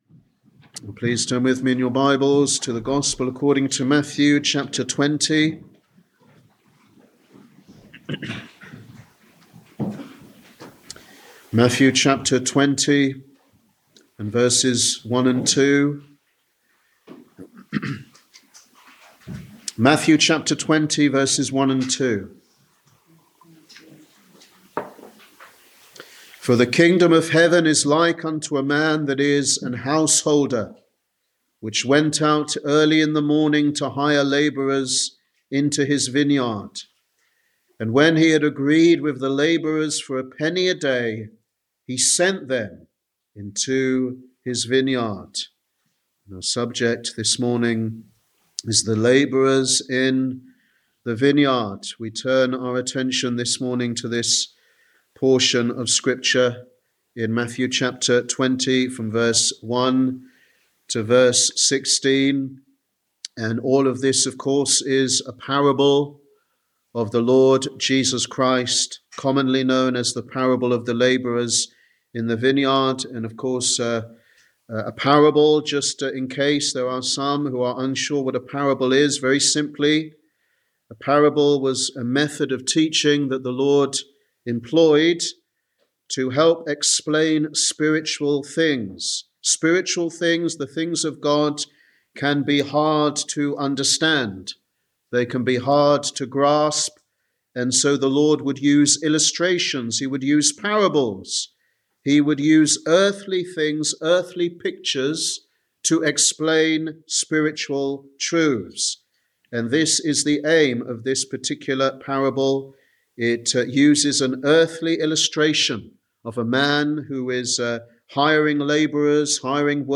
Sunday Evangelistic Service
Sermon